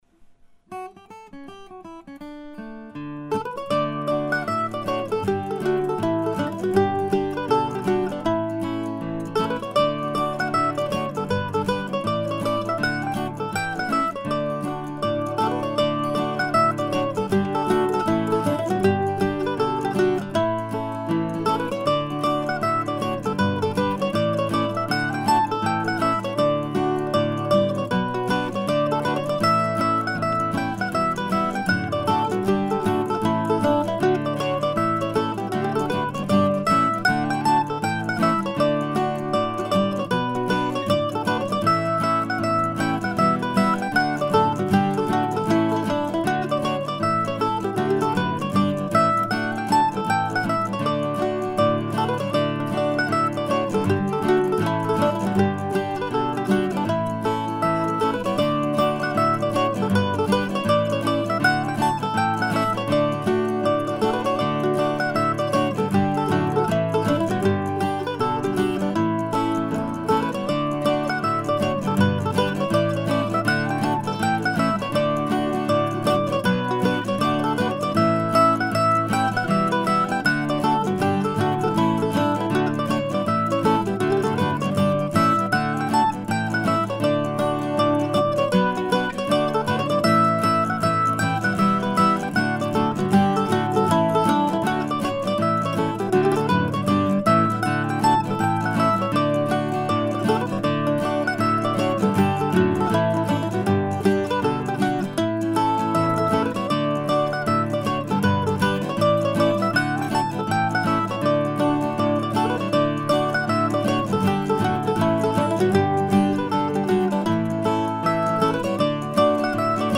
The Coast is Clear ( mp3 ) ( pdf ) A friendly hornpipe in D from September of 2005.